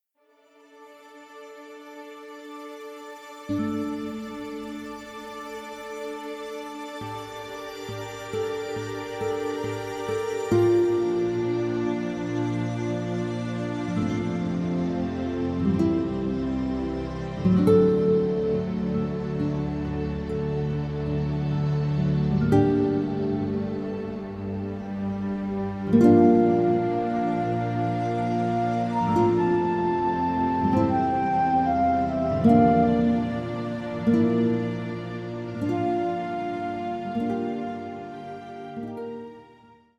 This is an instrumental backing track cover.
• Key – Dm
• With Backing Vocals
• No Fade